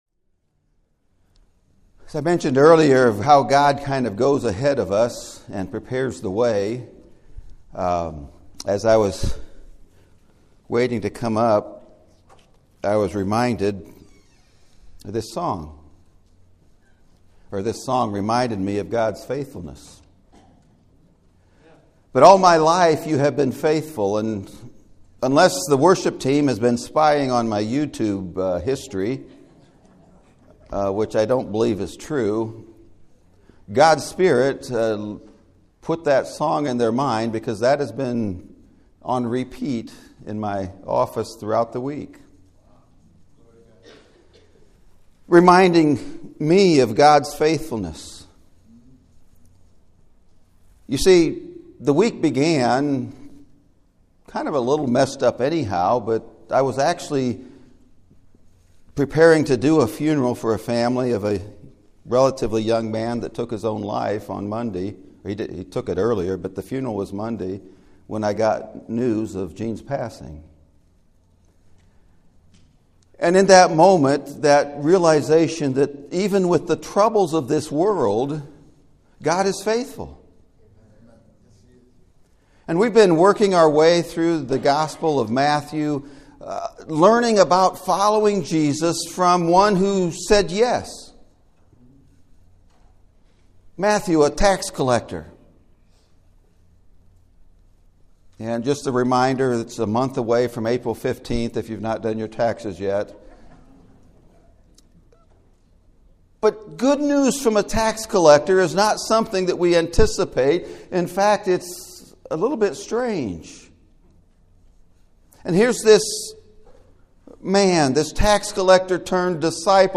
Following Jesus – Those Who Are SENT! (Sermon Audio)